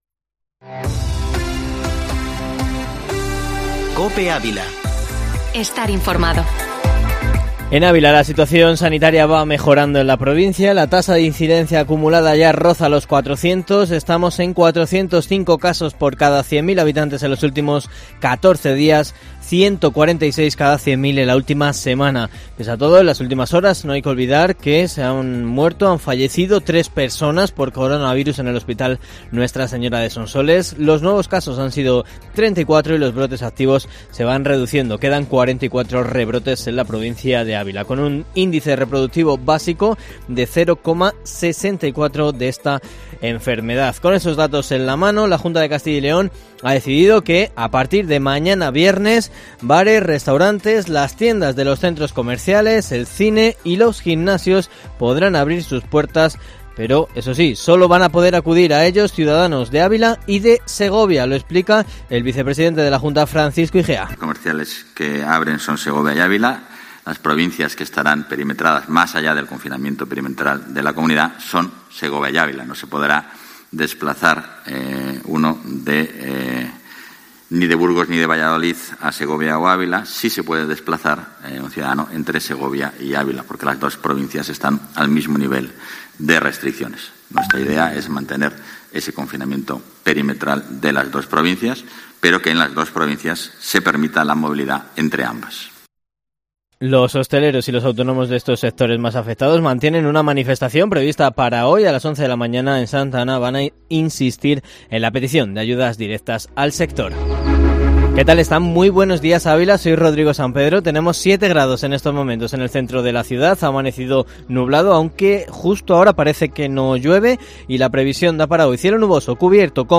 informativo local y provincial